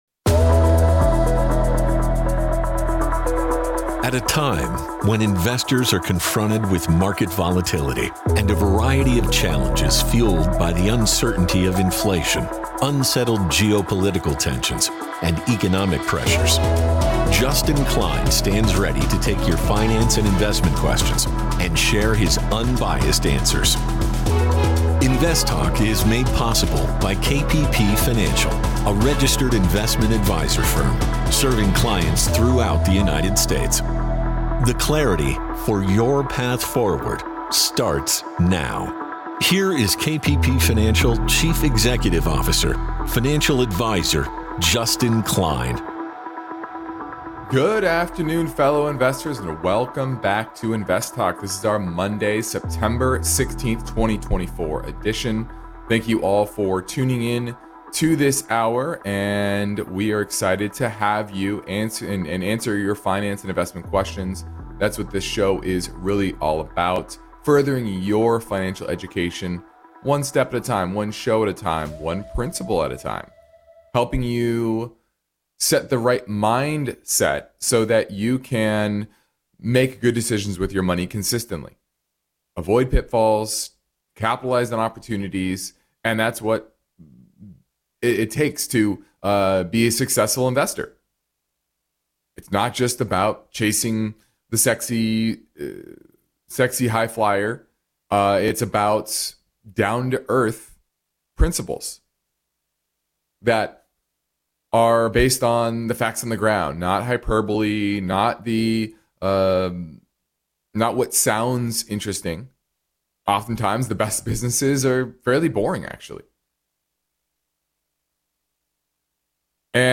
and a caller question on Options